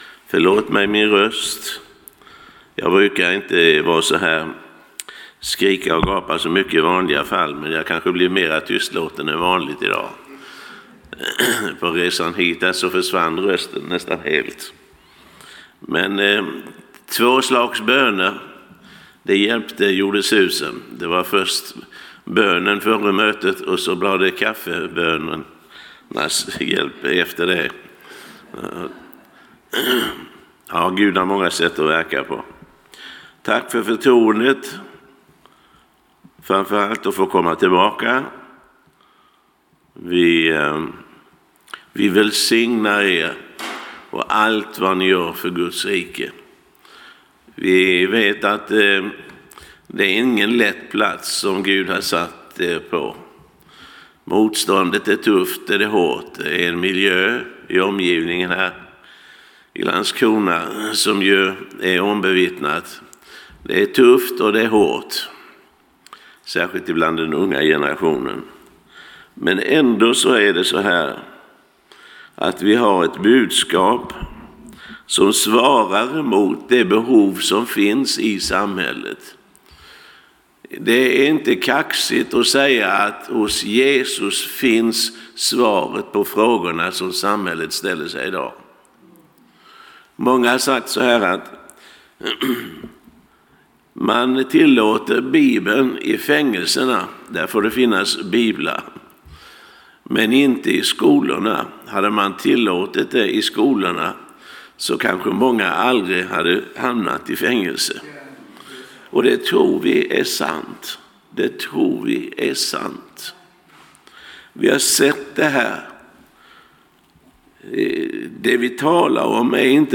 Det finns ett par ikoner uppe till höger där du kan lyssna på ”bara” predikan genom att klicka på hörlurarna eller ladda ner den genom att klicka på pilen.